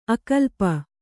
♪ akalpa